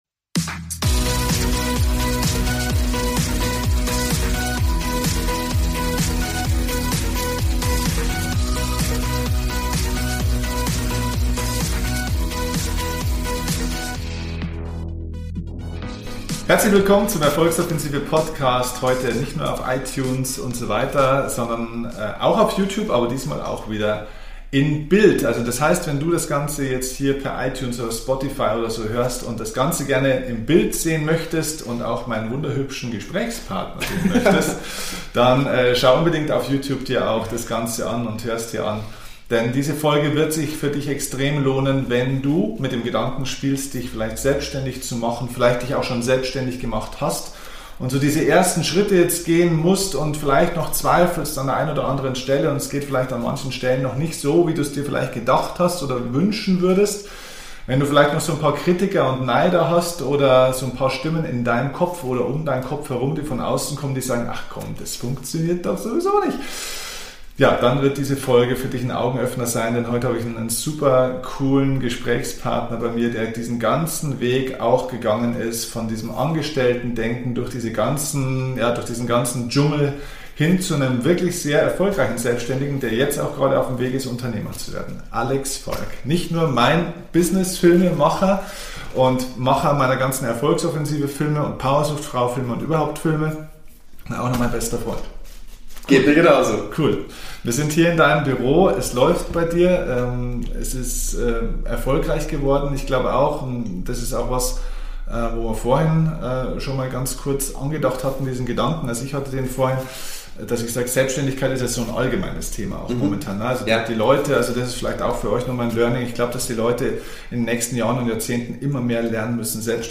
In dieser Folge spreche ich mit ihm über seine Anfänge in die Selbständigkeit und welche Hürde es zu überwinden gab. Ich bin mir sicher, wenn Du planst Dich selbstständig zu machen, oder erfolgreicher mit Deinem Unternehmen sein möchtest, bekommst Du heute hilfreiche Tipps!